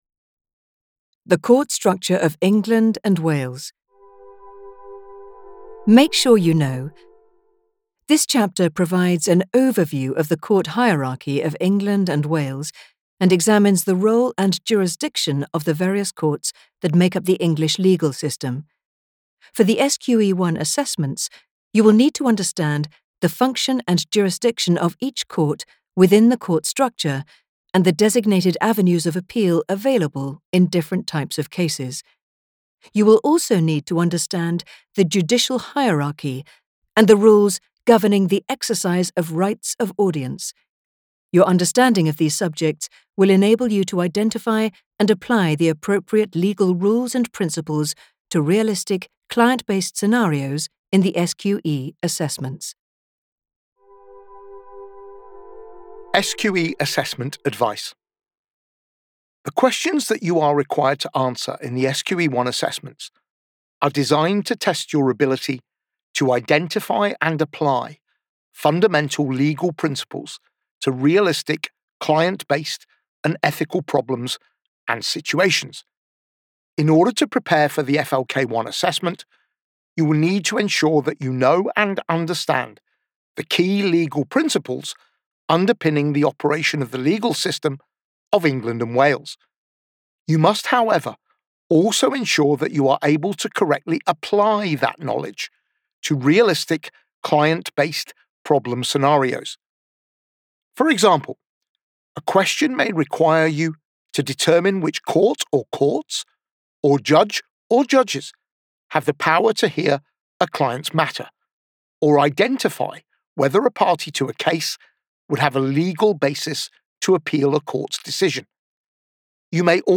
Audiobook Sample Free revision checklist